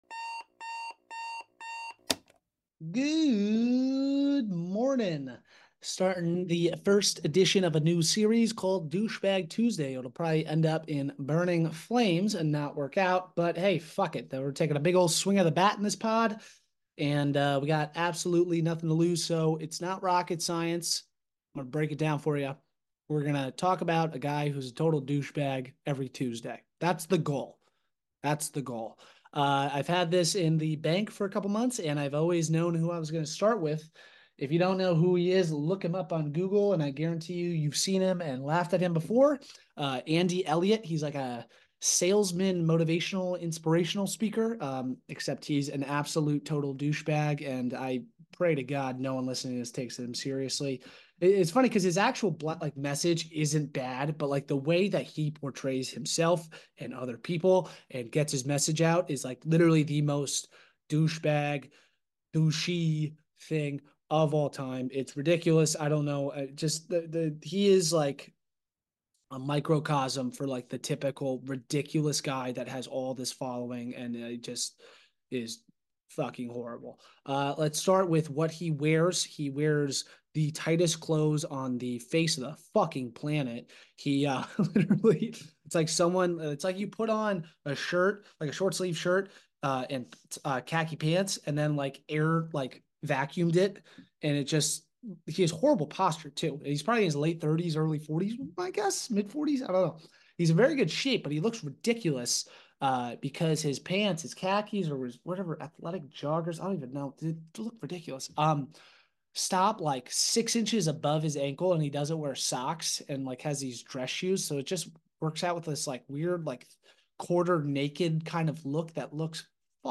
Genres: Comedy, Improv, Stand-Up